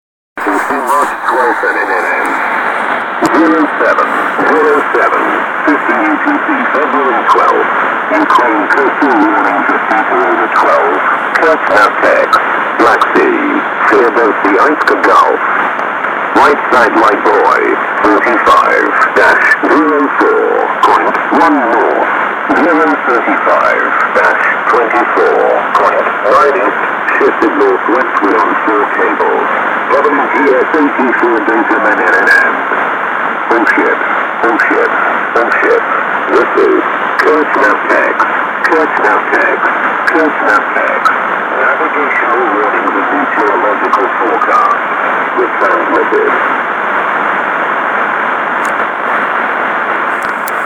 Начало » Записи » Записи радиопереговоров - корабли и береговые станции